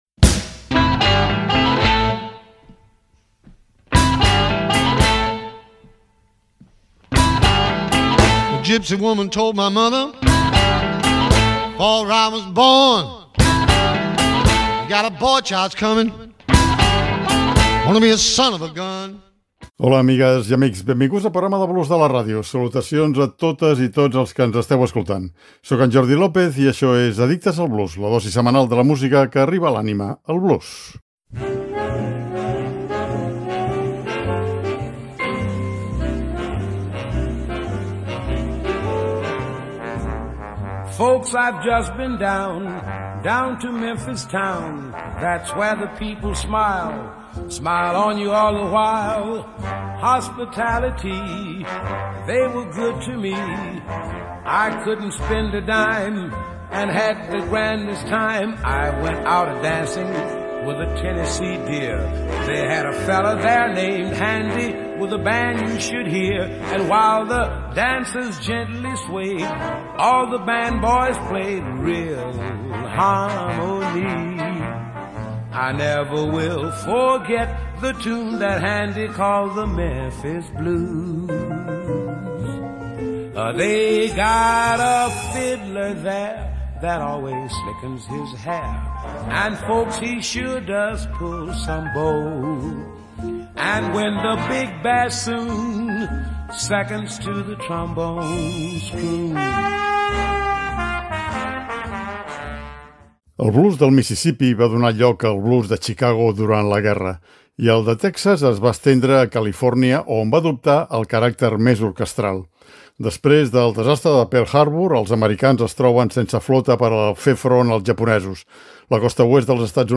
El blues del Mississippí va donar lloc al blues de Chicago durant la guerra, i el de Texas es va estendre a Califòrnia, on va adoptar un caràcter més orquestral.